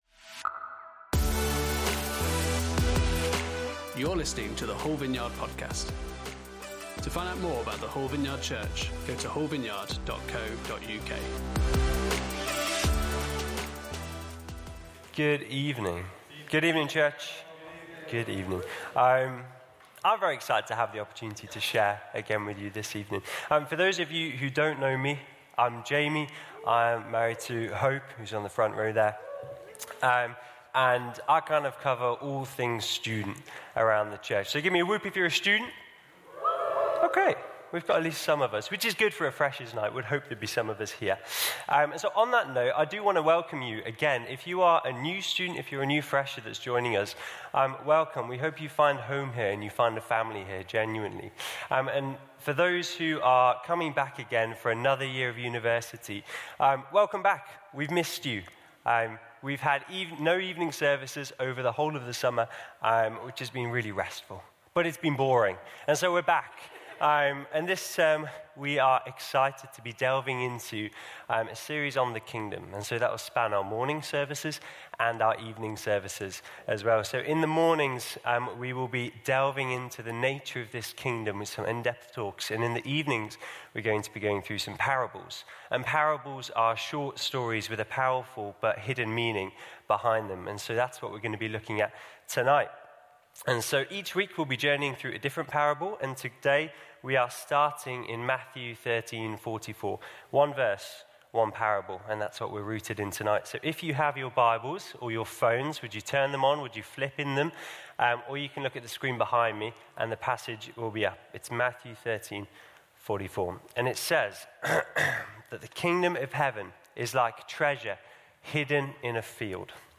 Parable Service Type: Sunday Service On Sunday evening